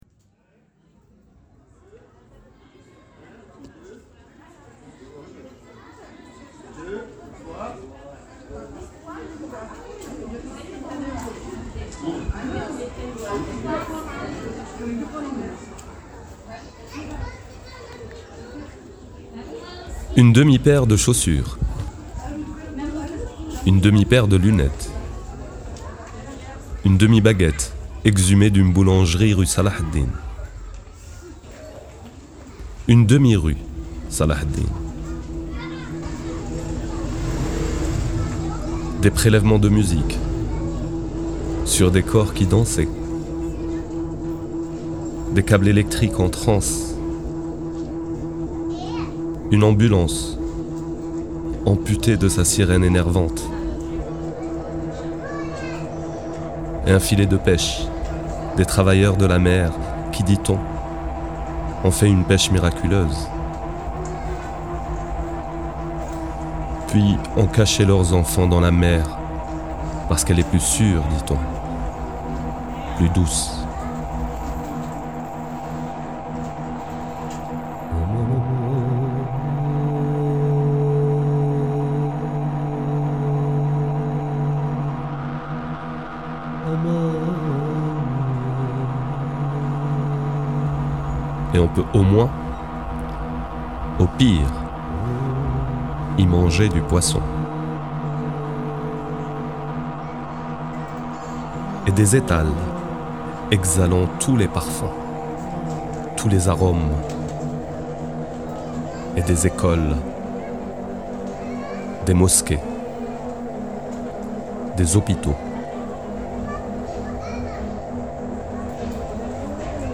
Lecture en français et en arabe, création musicale, enregistrement et mixage